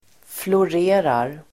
Uttal: [flor'e:rar]